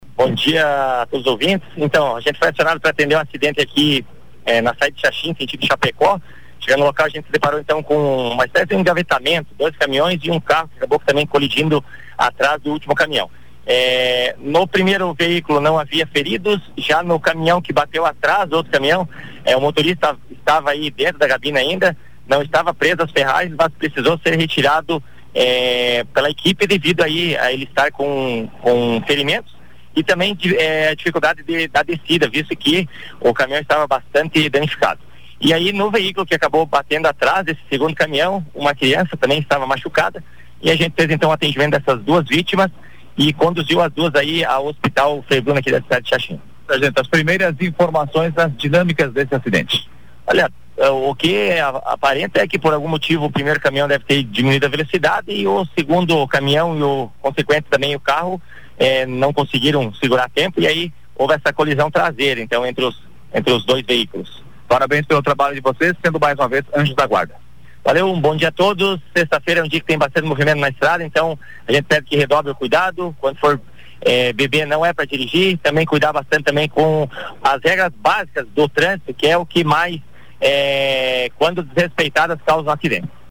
Podcast reportagem